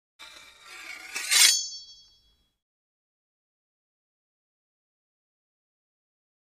Sword Shing 1; Sword Edges Run Against Each Other.